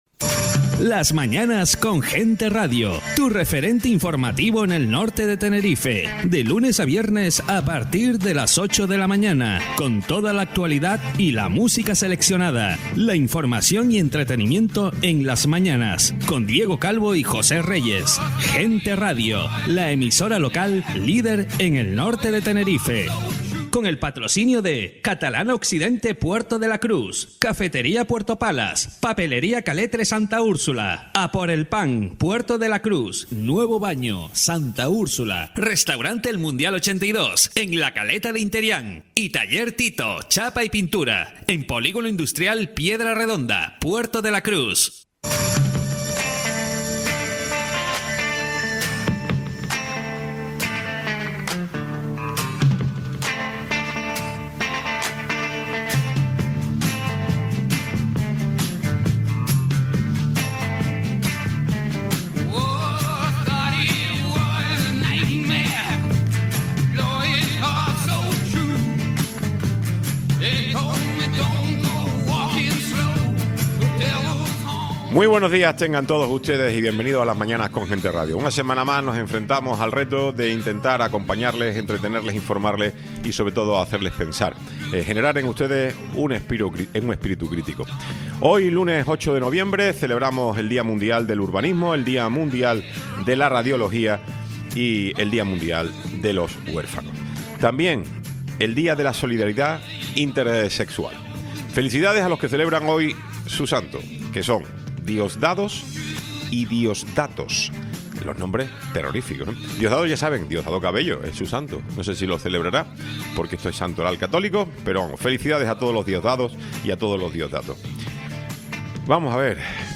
Tiempo de entrevista con Fernando Enseñat Bueno, Diputado autonómico PP Tiempo de entrevista con Rosa Dávila, Diputada autonómica CC